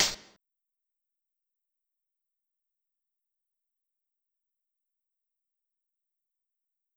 Closed Hat (Whoa).wav